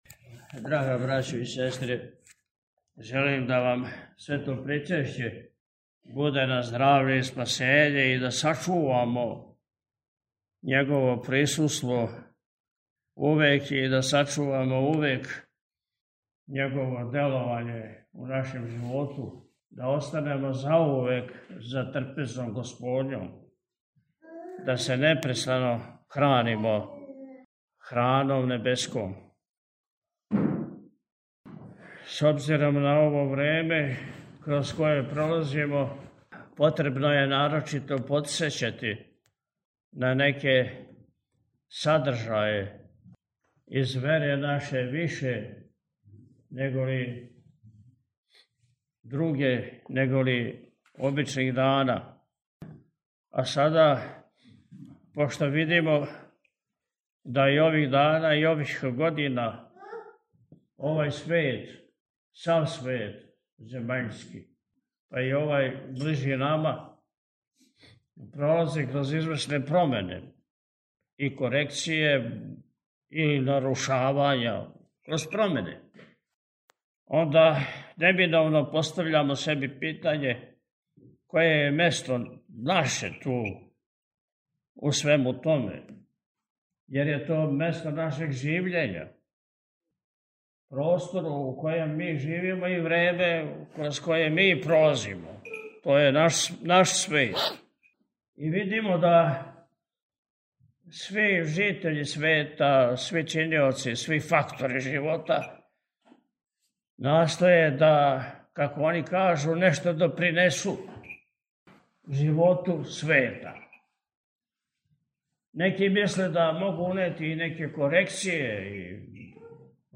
Честитајући присутнима учешће у Божанској Литургији и причешће Божанским Даровима Високопреосвећени је у пастирској беседи, након отпуста, рекао: – Пошто видимо да и ових дана, а и ових година, сав свет земаљски пролази кроз извесне промене, корекције, и нарушавања, неминовно себи постављамо питање које је наше место у свему томе, јер је то место нашег живљења, простор у којем живимо и време кроз које пролазимо.
beseda-2.subota-posta.mp3